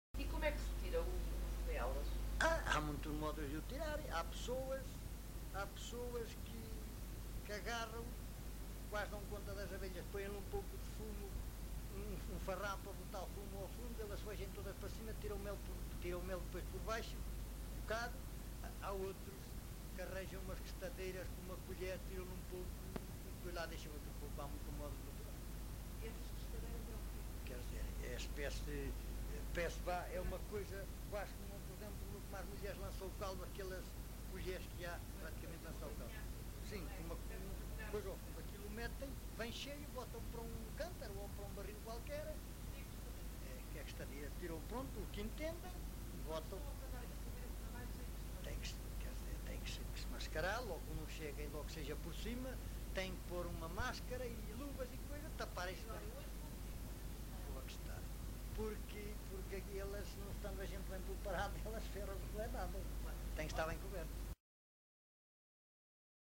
LocalidadeLarinho (Torre de Moncorvo, Bragança)